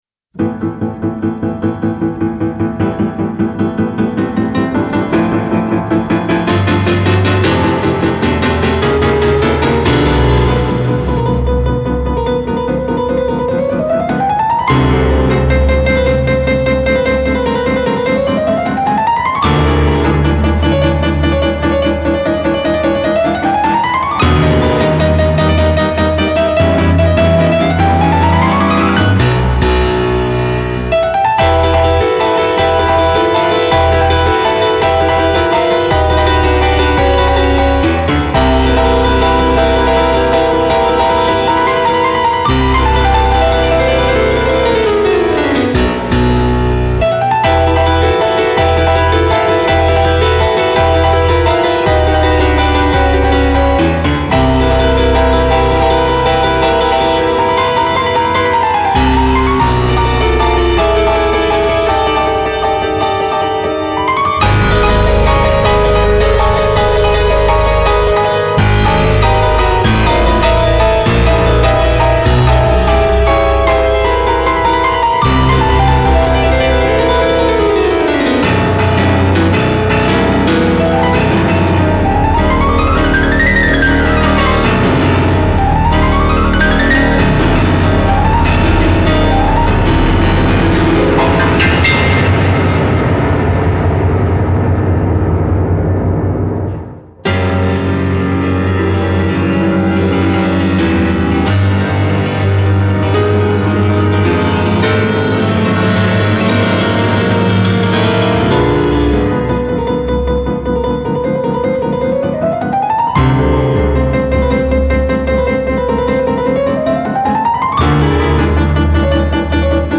【所属类别】音乐 新世纪音乐
万众瞩目古典跨类音乐界又一超级巨星，洗练演奏技巧+现代节奏元素，呈现惊为天人的钢琴现代风潮。